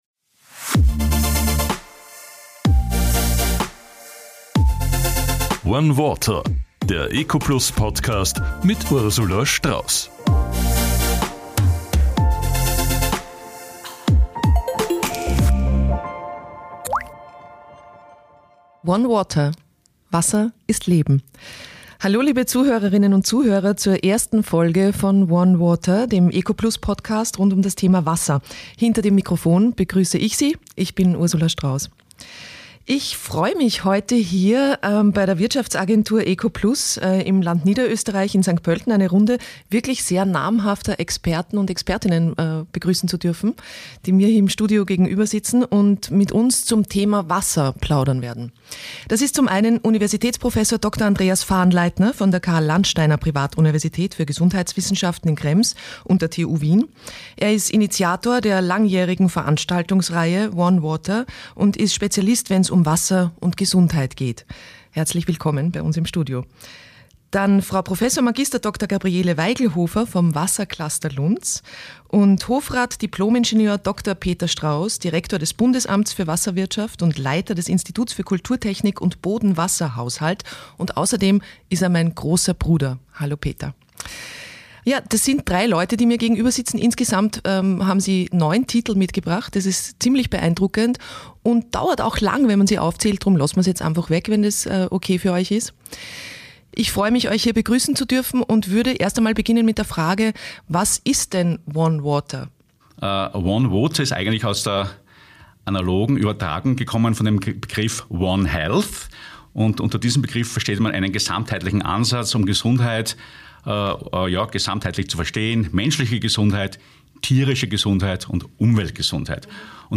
Wasser ist die lebenswichtigste Ressource auf unserem Planeten, und die gilt es zu schützen! Ursula Strauss freut sich zu diesem Thema auf ein interessantes Gespräch mit drei namhaften ExpertInnen im neuen ecoplus-Podcast „ONE WATER“.
Drei Wasser-ExpertInnen geben einen kurzen Überblick über Wasser-Themen, die in den folgenden Serien genauer behandelt werden